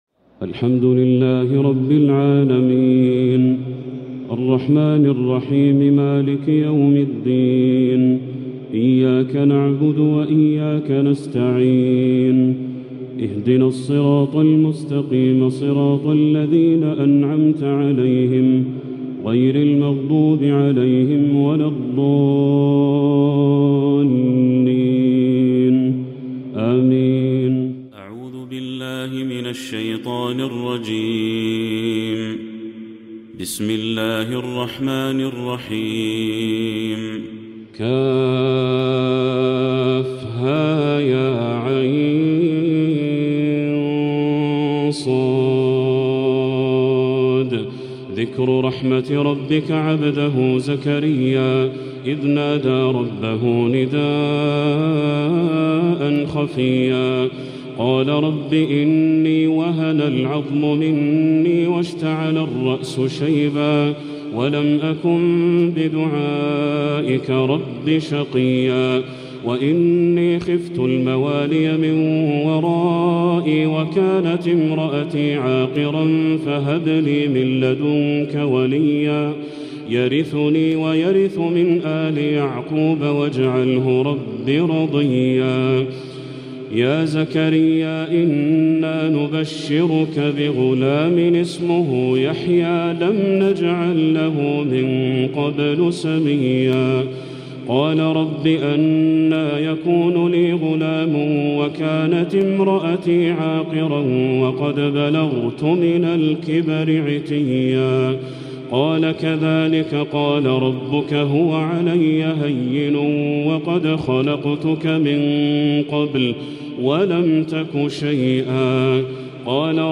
السور المكتملة من ليالي رمضان 1445هـ من الحرم المكي للشيخ بدر التركي♥🕋 > السور المكتملة للشيخ بدر التركي من الحرم المكي 🕋 > السور المكتملة 🕋 > المزيد - تلاوات الحرمين